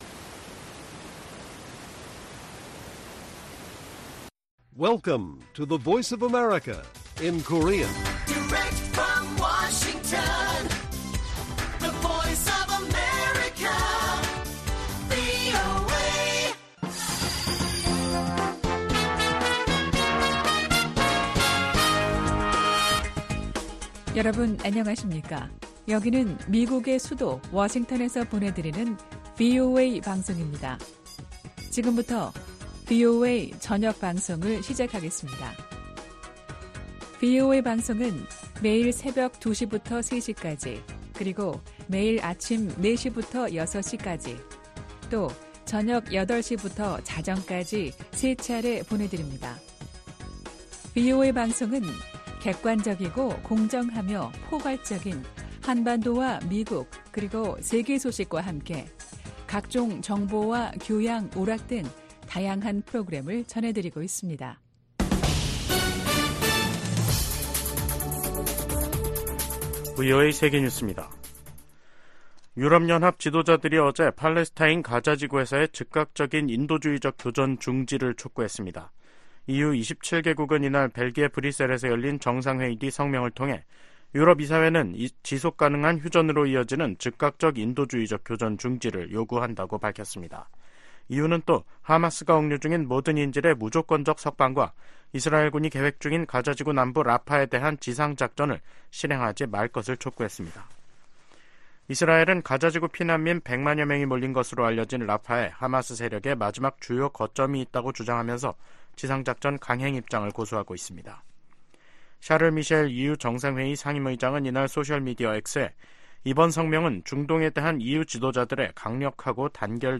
VOA 한국어 간판 뉴스 프로그램 '뉴스 투데이', 2024년 3월 22일 1부 방송입니다. 미국-한국 전시작전권 전환 작업이 완료를 위한 궤도에 있다고 폴 러캐머라 주한미군사령관이 밝혔습니다. 북한이 대륙간탄도미사일(ICBM)로 핵탄두를 미 전역에 운반할 능력을 갖췄을 것이라고 그레고리 기요 미 북부사령관 겸 북미 항공우주방위사령관이 평가했습니다. 윤석열 한국 대통령은 ‘서해 수호의 날'을 맞아, 북한이 도발하면 더 큰 대가를 치를 것이라고 경고했습니다.